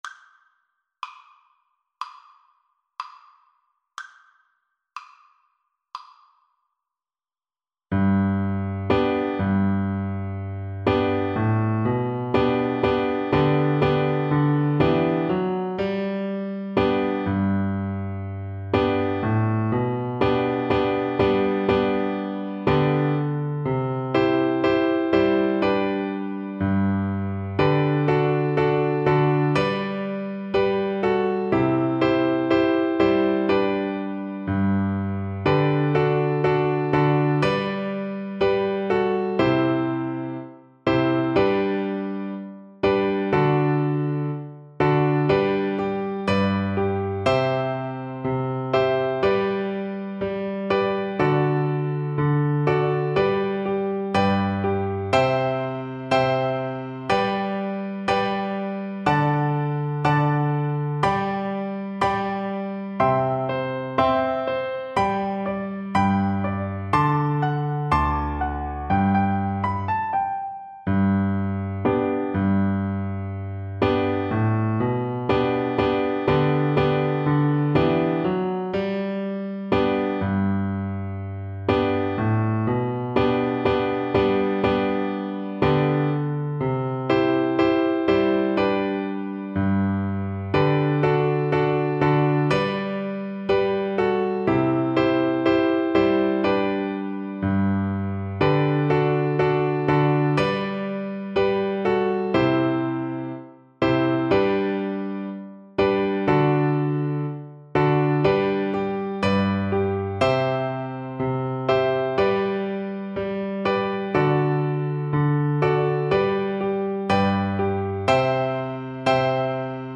Flute version
G major (Sounding Pitch) (View more G major Music for Flute )
Joyfully =c.100
4/4 (View more 4/4 Music)
Flute  (View more Easy Flute Music)
Traditional (View more Traditional Flute Music)